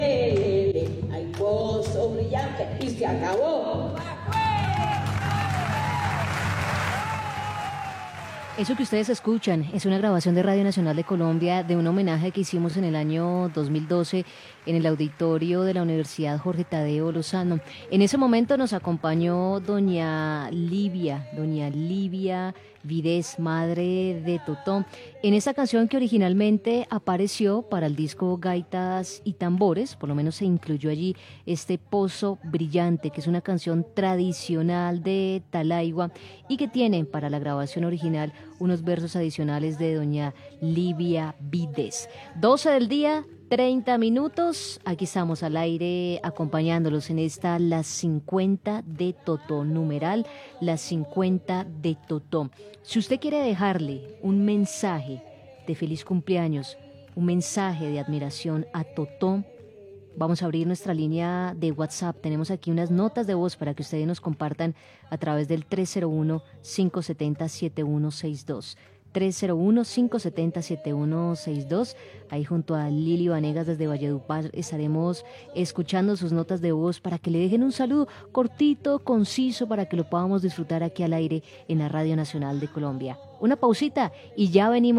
Radio Nacional de Colombia recuerda el homenaje musical hecho a Totó La Momposina en el Auditorio Fabio Lozano
Sucedió en el programa especial “Las 50 de Totó”, un homenaje a la cantante del Caribe en esta radio publica.